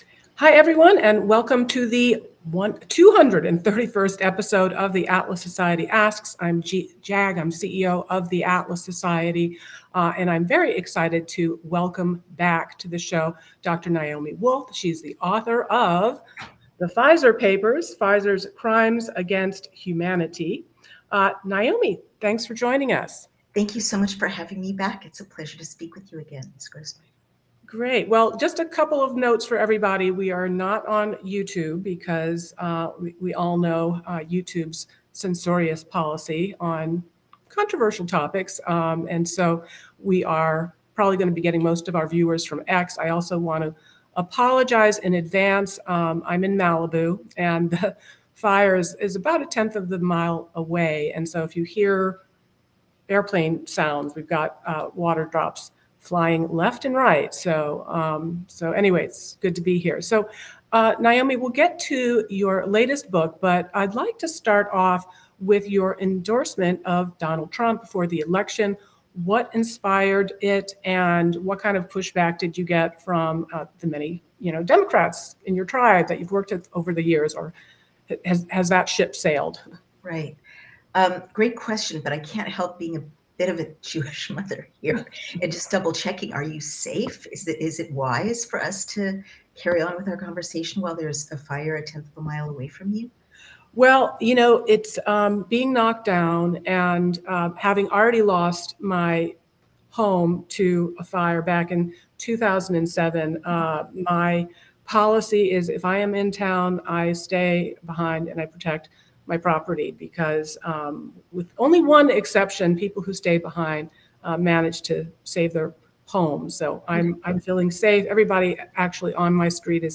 where she interviews returning guest, Dr. Naomi Wolf about her latest book, The Pfizer Papers: Pfizer’s Crimes Against Humanity.